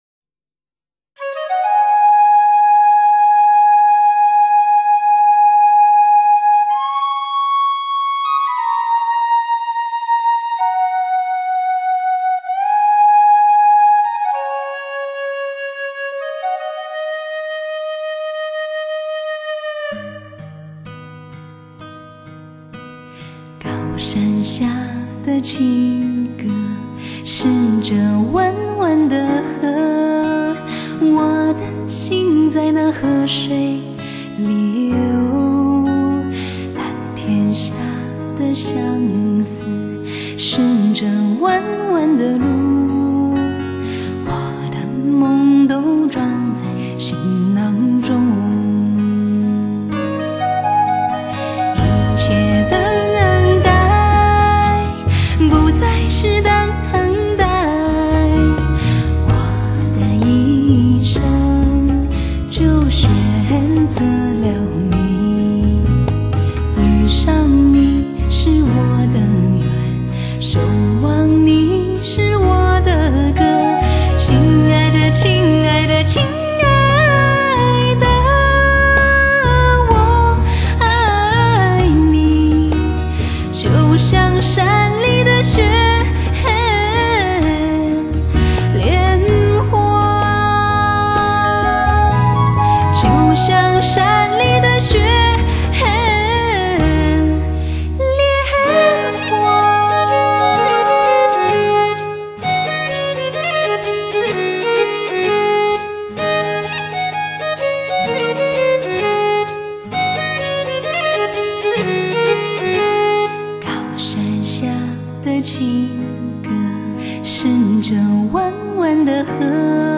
悠扬旋律，绝美声线，让人不断涌起电影中凄美绝伦的画面！